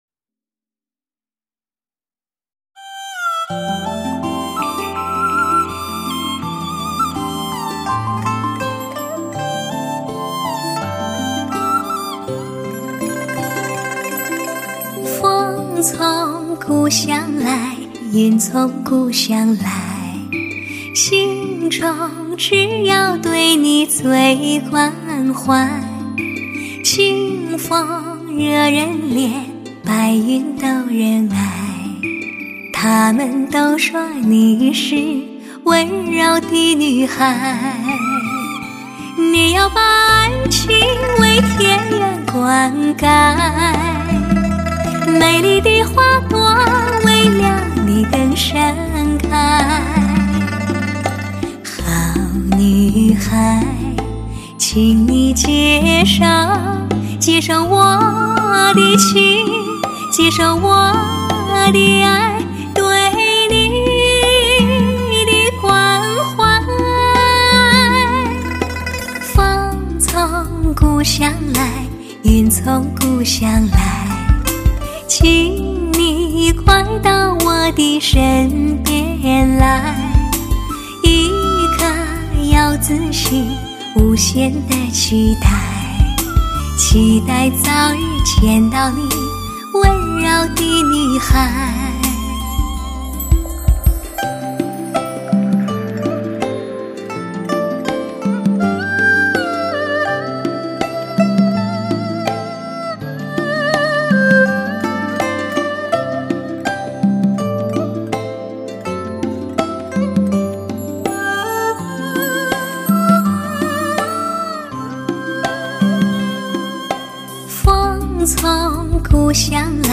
完美的HI-FI效果，迷醉了挑剔的耳朵，
贴耳的吟唱，贴心的舒服，听醉了你的耳朵，征服了你的灵魂。